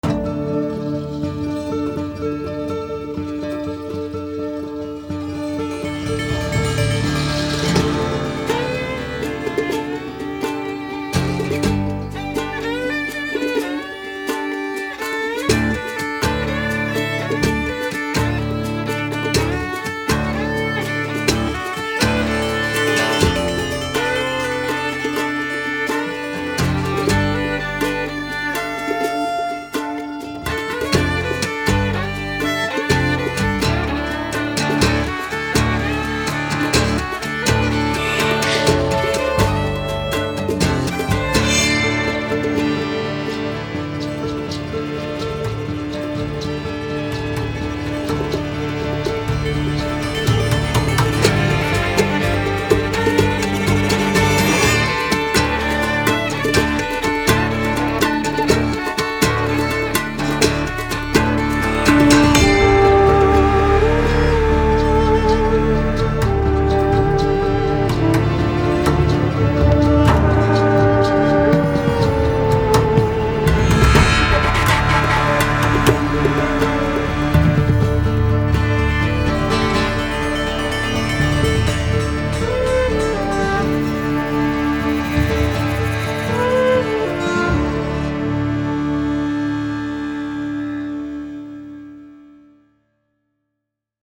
Main Title theme music